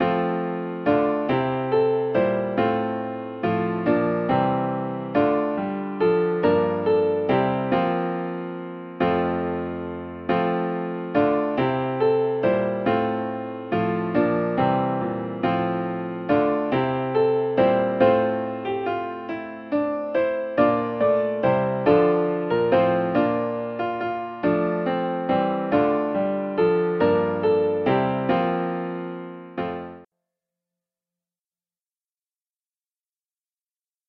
552-HolyHolyHoly_accomp.mp3